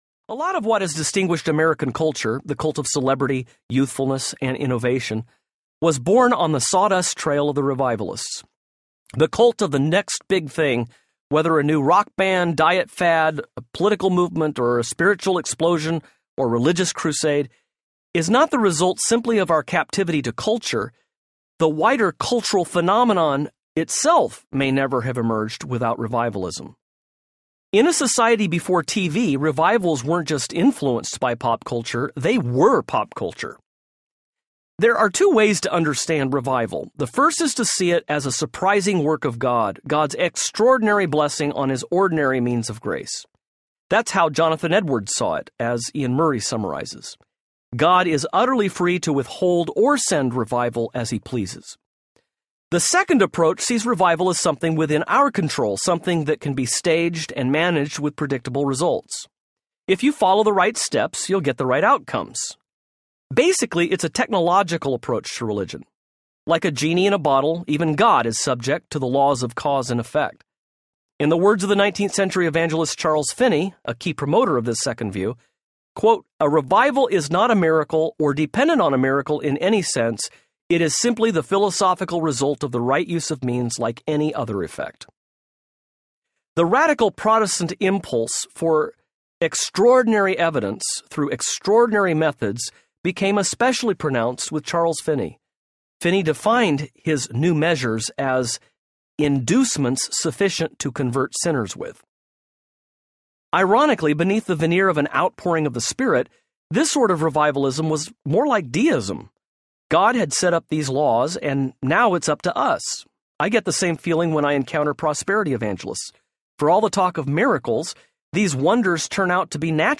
Ordinary Audiobook
7.3 Hrs. – Unabridged